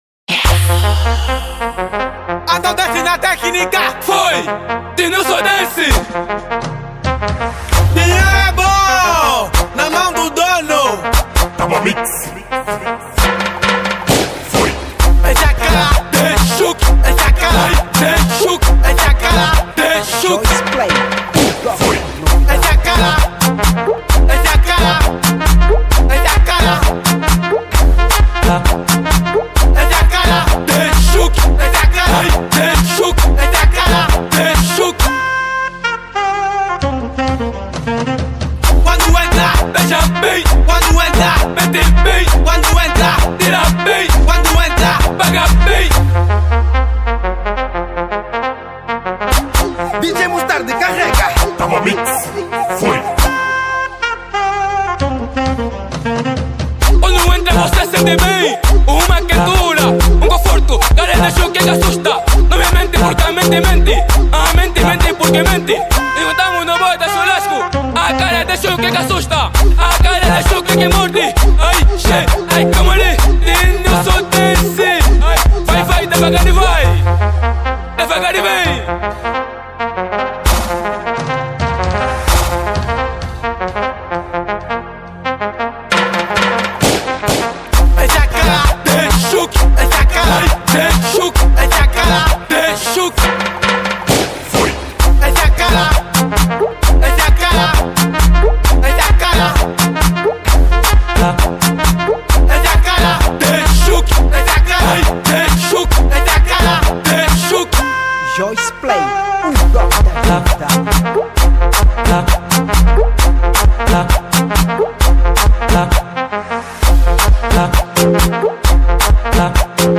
Género: Afro House